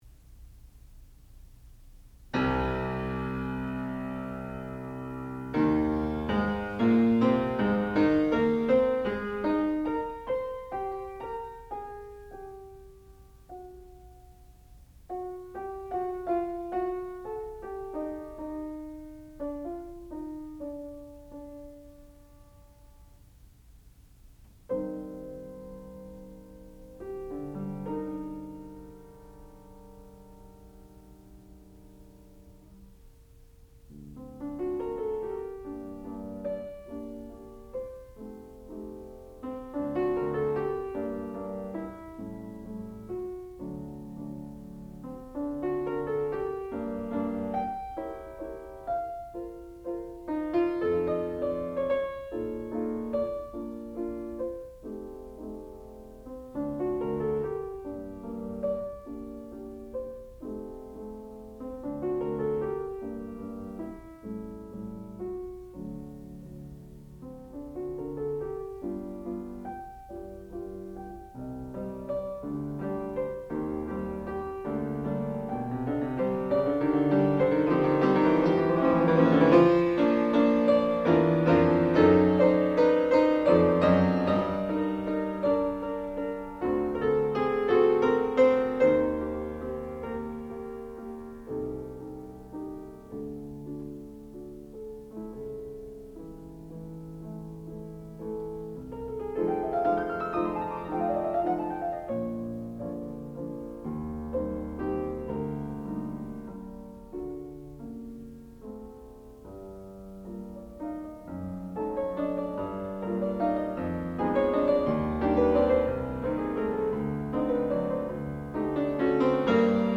sound recording-musical
classical music
Advanced Degree Recital
piano